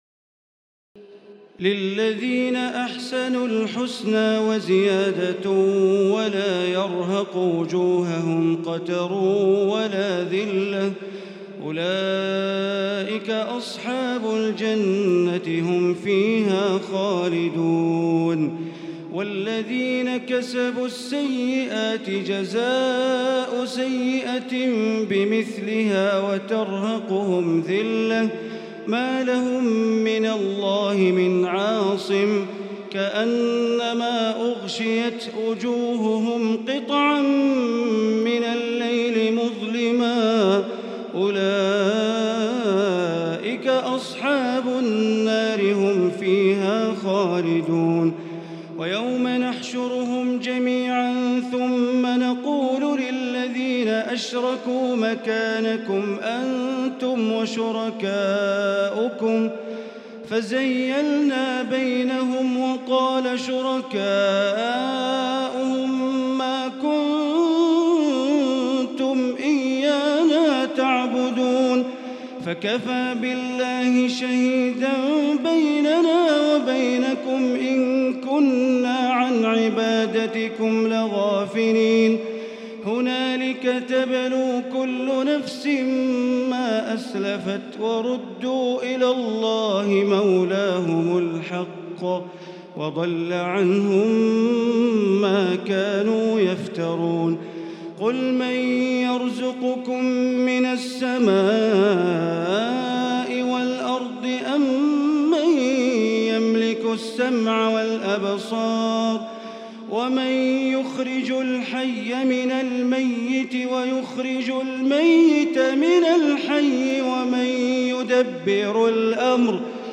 تراويح الليلة العاشرة رمضان 1438هـ من سورة يونس (26-109) Taraweeh 10 st night Ramadan 1438H from Surah Yunus > تراويح الحرم المكي عام 1438 🕋 > التراويح - تلاوات الحرمين